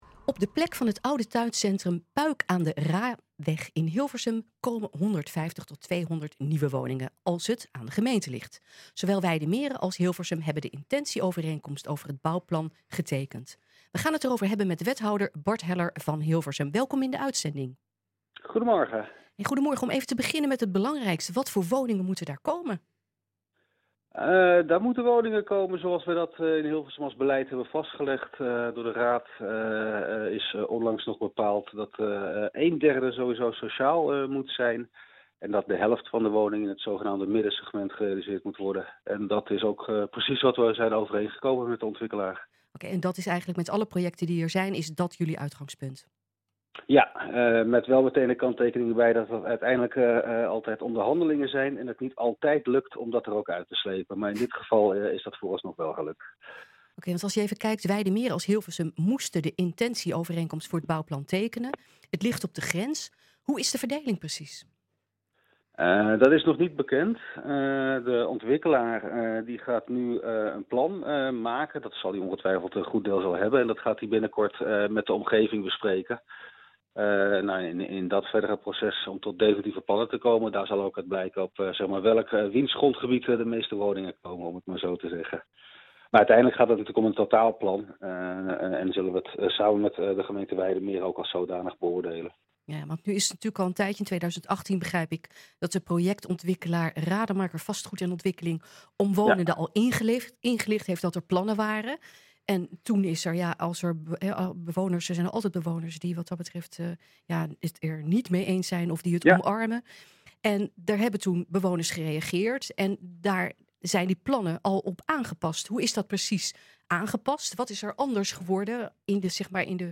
Op de plek van het oude tuincentrum Puik aan de Raaweg in Hilversum komen 150 tot 200 nieuwe woningen, als het aan de gemeente ligt. Zowel Wijdemeren als Hilversum hebben de intentieovereenkomst over het bouwplan getekend. We gaan het erover hebben met wethouder Bart Heller van Hilversum.